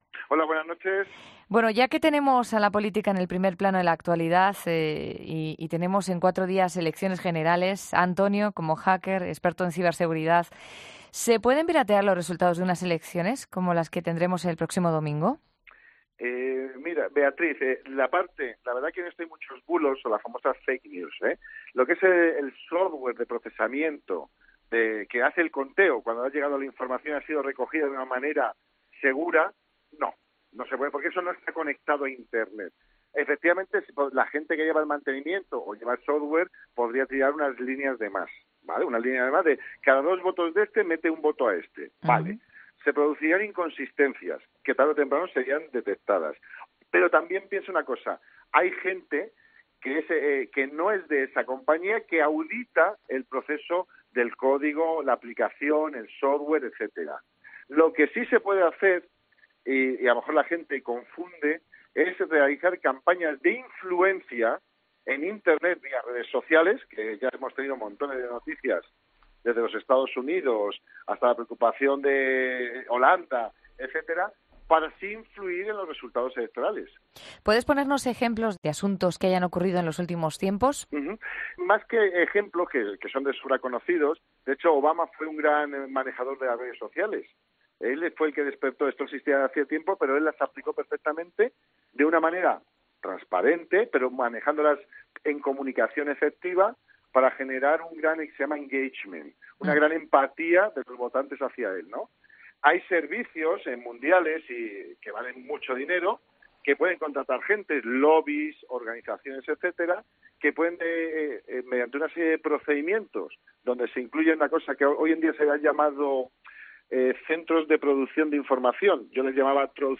Un experto en ciberseguridad responde en 'La Noche'